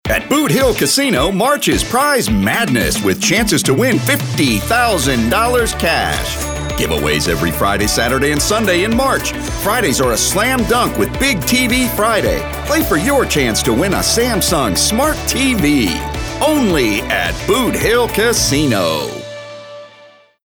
announcer, authoritative, Booming, bravado, confident, hard-sell, high-energy, middle-age, promo, upbeat